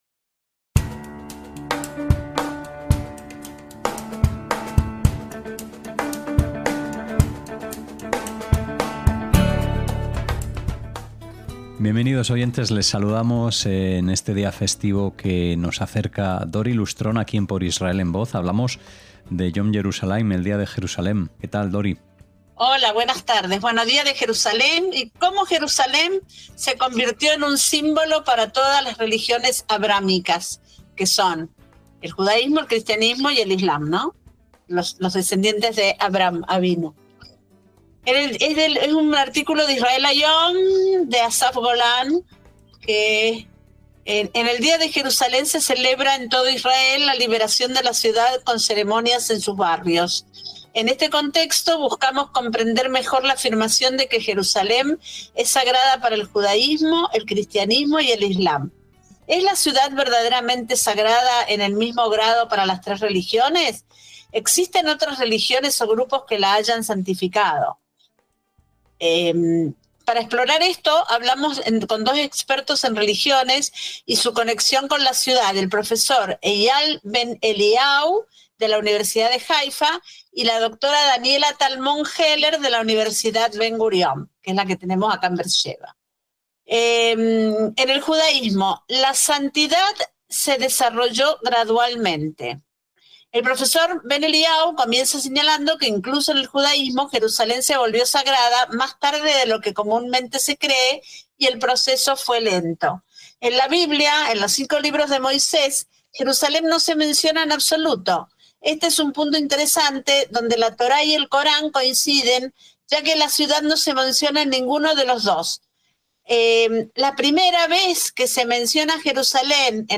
pone precisamente voz a las palabras que describen la celebración de este día. Nos trasladamos a una ciudad sagrada para las tres religiones y conoceremos su importancia para cada una de ellas.